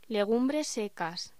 Locución: Legumbres secas
voz